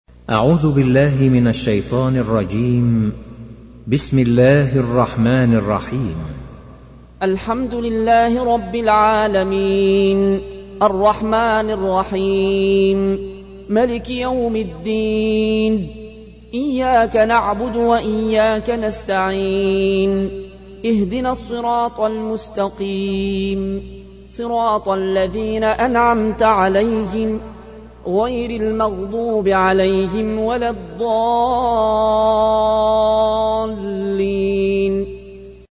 تحميل : 1. سورة الفاتحة / القارئ العيون الكوشي / القرآن الكريم / موقع يا حسين